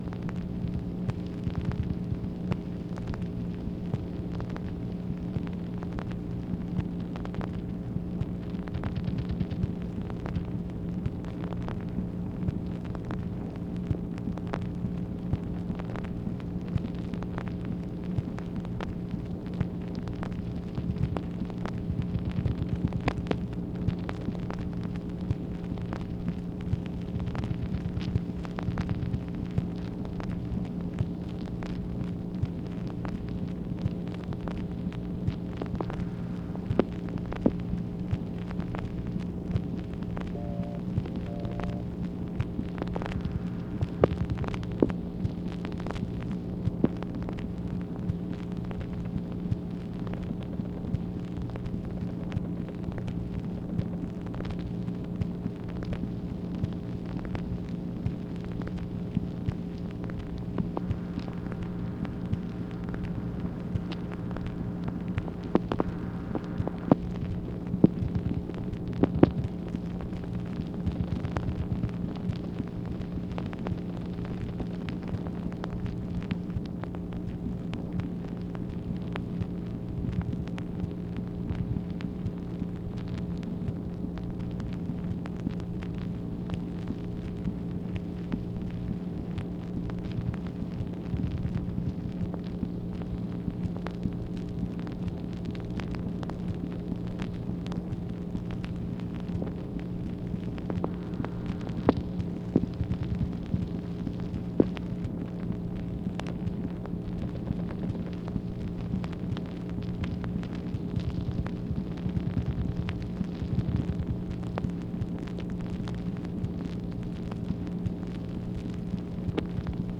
MACHINE NOISE, August 23, 1965
Secret White House Tapes | Lyndon B. Johnson Presidency